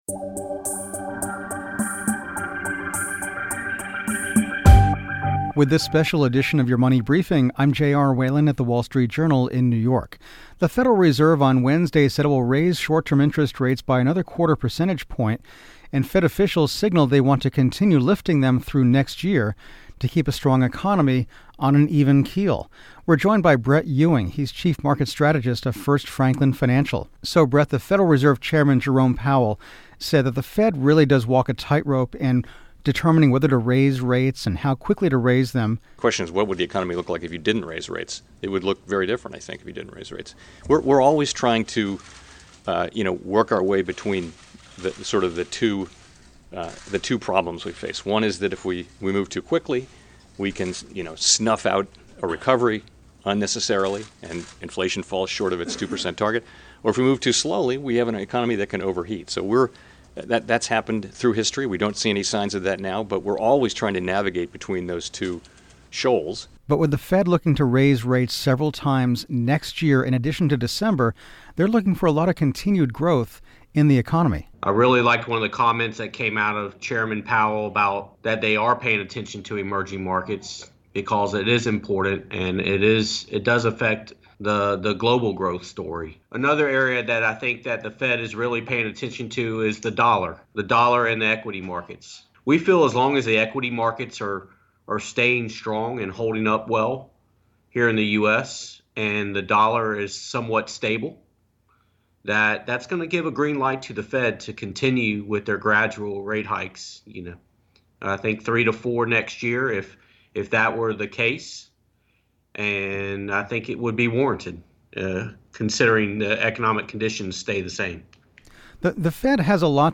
Special Fed Coverage: Powell Press Conference